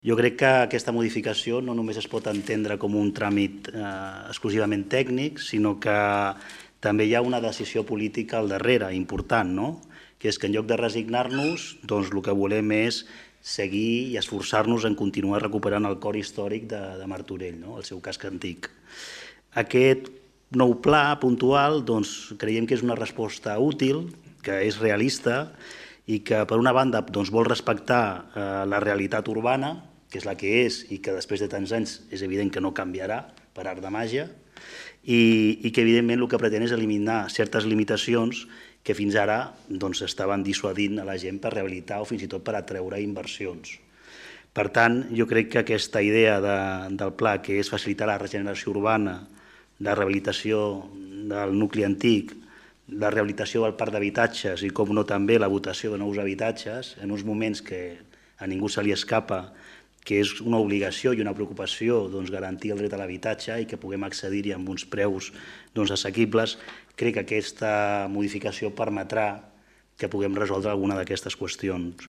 L’Ajuntament de Martorell va aprovar, en el Ple Ordinari d’ahir a la nit, una modificació puntual del Pla General d’Ordenació Urbana (PGOU) de Martorell de gran rellevància per La Vila.
Javier González, regidor Transici´Digital i Sostenible